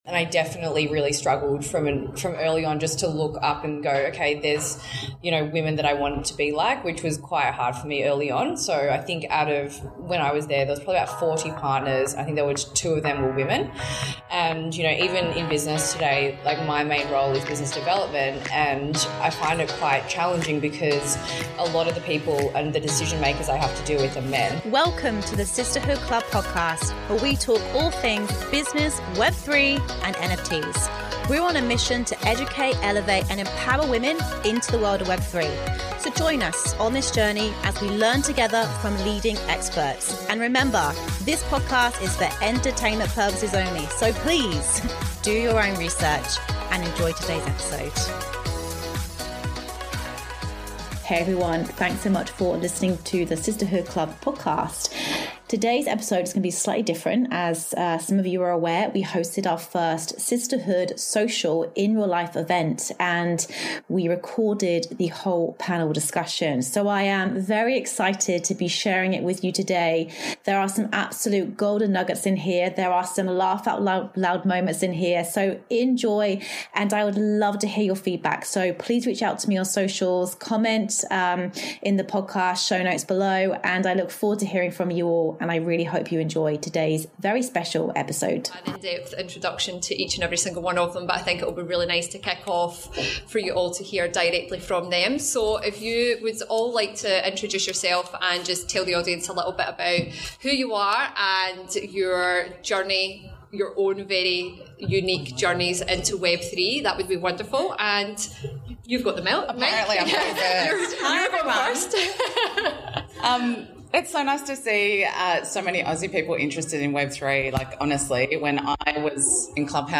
Women in Web3 Panel